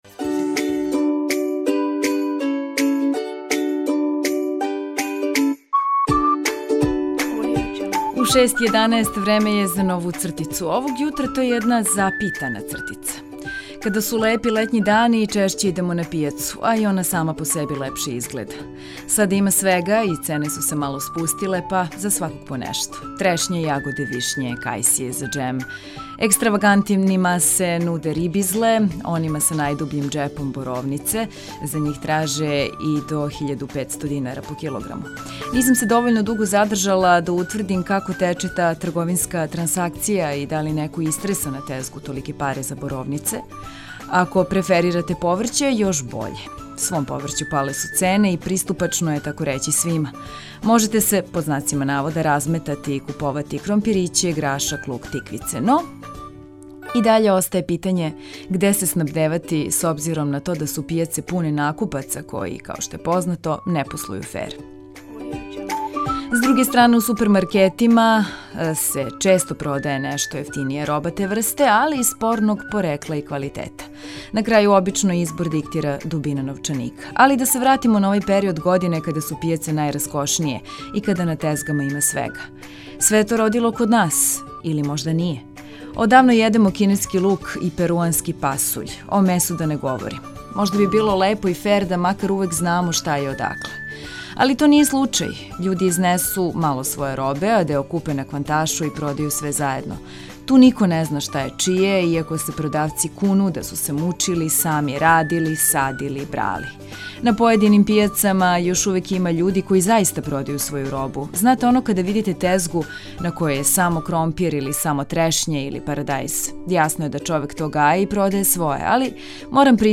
Разбуђивање уз важне информације, ведру музику и много разлога за осмех које нудимо, биће идеалан бег из урбане џунгле у којој већина нас живи.